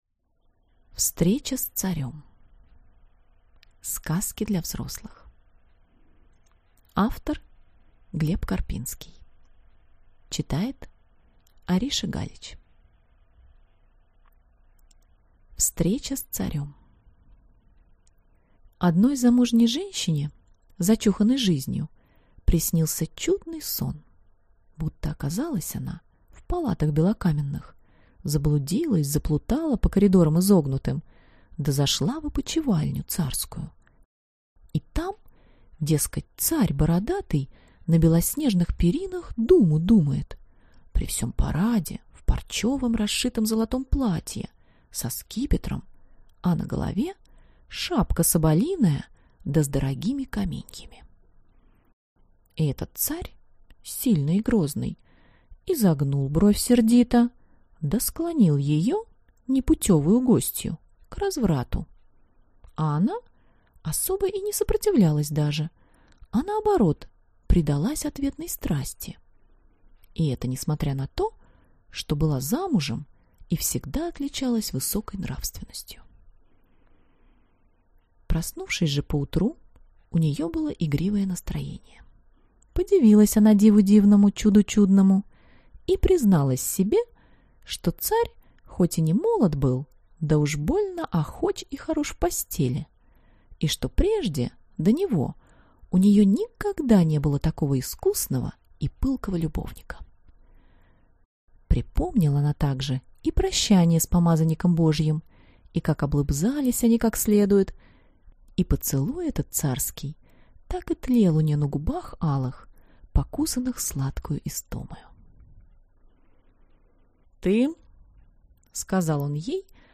Aудиокнига Встреча с царем.